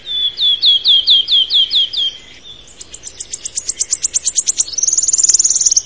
Luì verde
Phylloscopus sibilatrix
Richiamo ‘tzip’. Il canto, monotono e accelerato, inizia lentamente e termina in un trillo ‘t t t ttttt trrrrrr’, spesso seguito da un dolce ‘tiu tiu tiu’.
Lui_Verde_Phylloscopus_sibilatrix.mp3